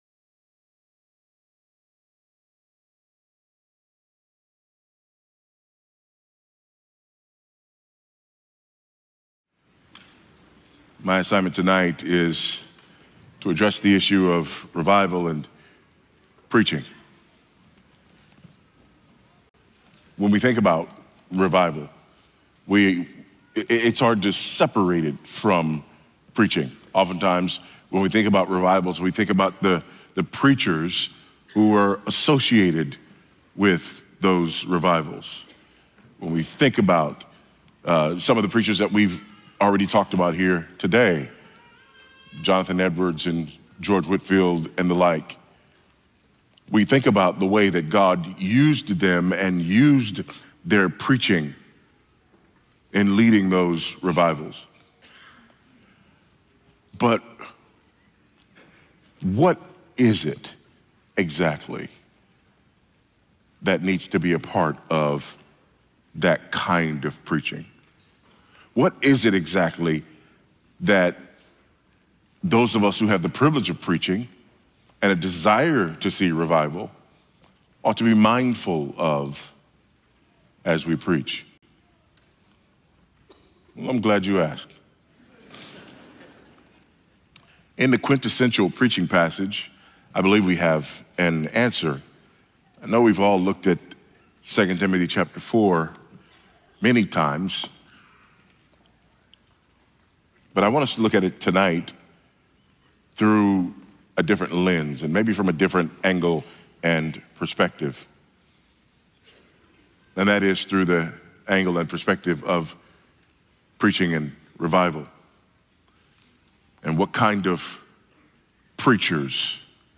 2025 at the "Revive Us, O Lord!" 2025 National Founders Conference in Fort Myers, Florida.
Sermons